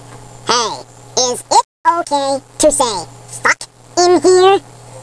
Elf voice: